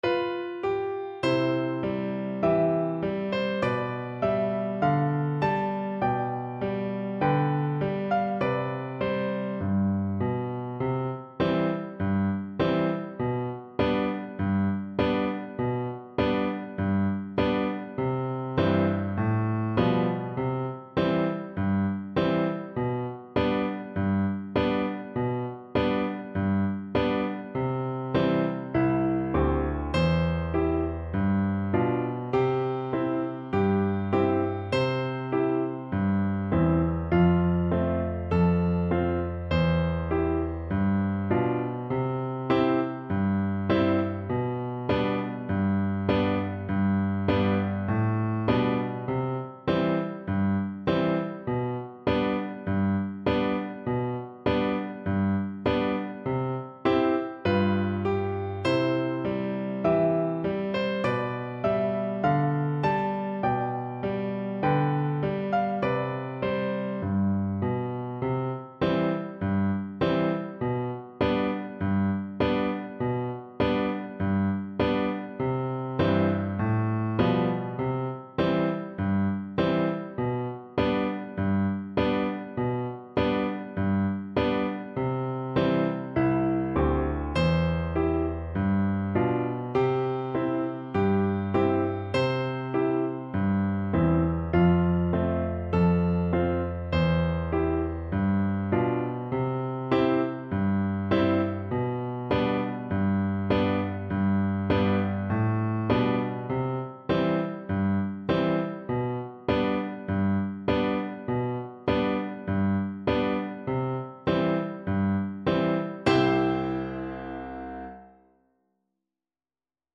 2/4 (View more 2/4 Music)
Steadily =c.76